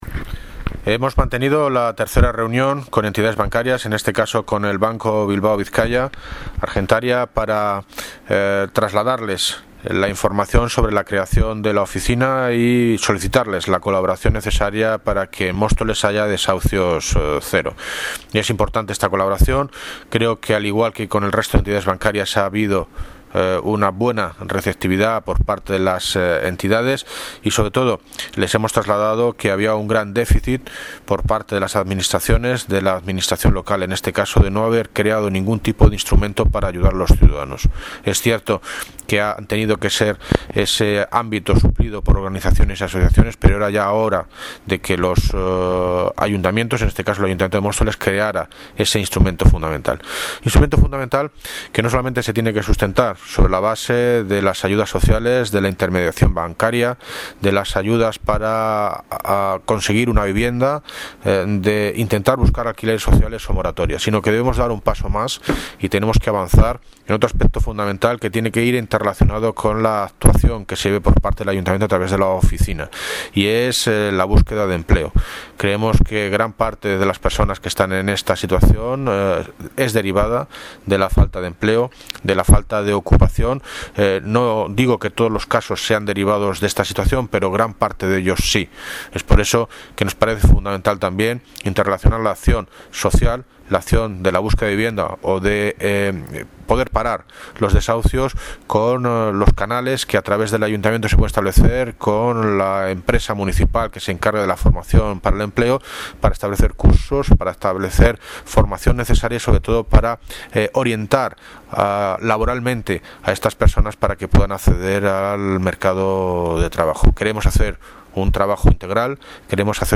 Audio - David Lucas (Alcalde de Móstoles) sobre oficina antidesahucios reunión BBVA